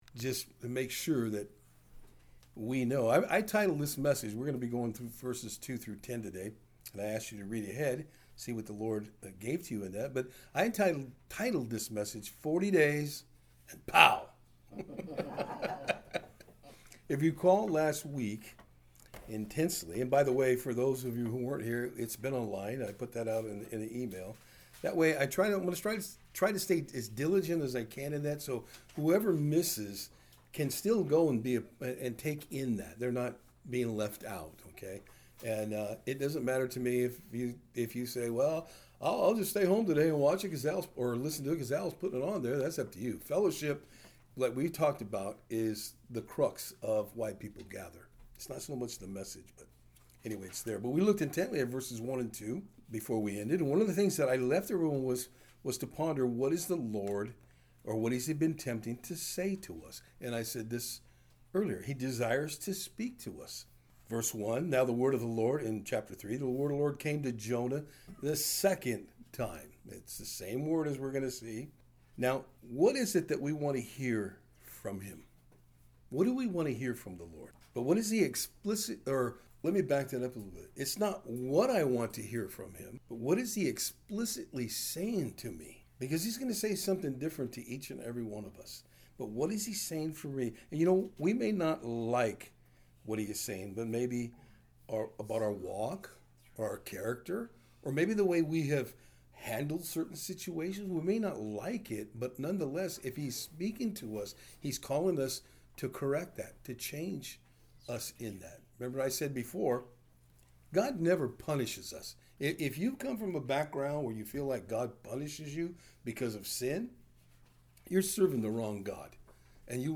Jonah 3:3-10 Service Type: Thursday Afternoon Today we will be looking at how Jonah relents and does what the Lord sent him to do.